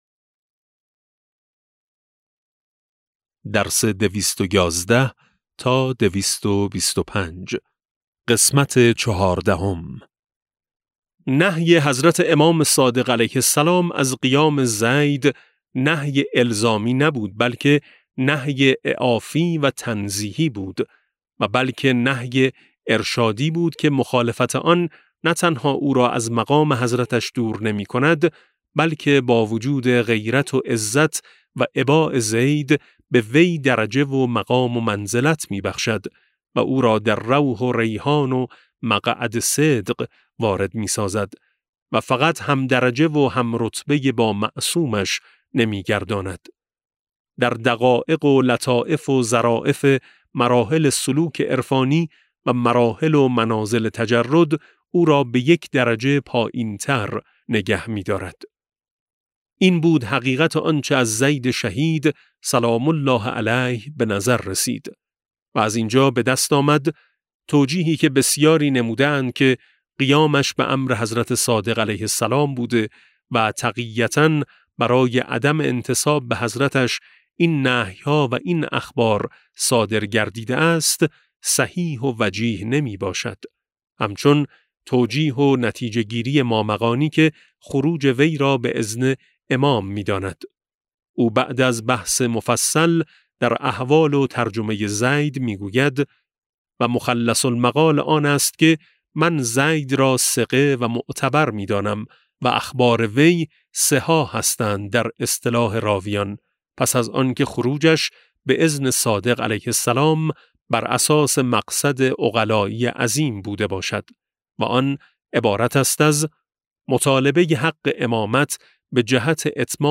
کتاب صوتی امام شناسی ج15 - جلسه14